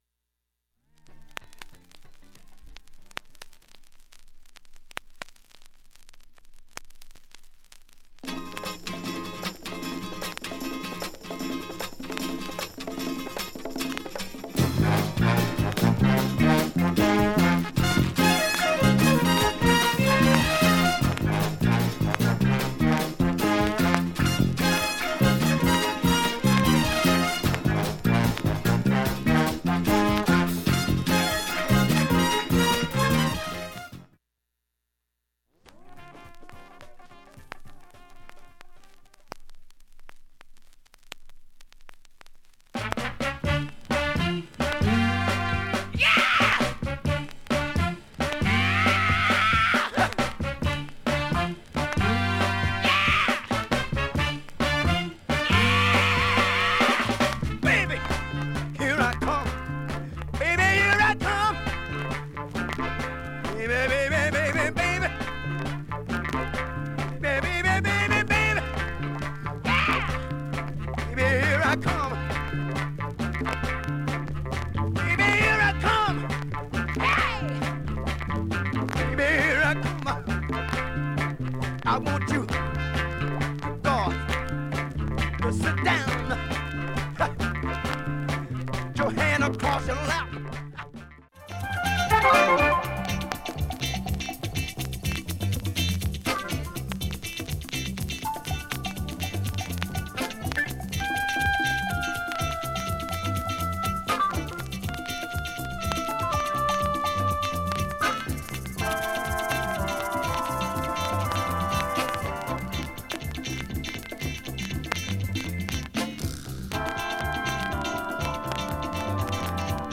チリプツが出ている箇所あります、
軽いチリプツ出ています（試聴は２分ほど）
5,(4m34s〜)B-6始め周回プツ出ますがかすかです。
単発のかすかなプツが１０箇所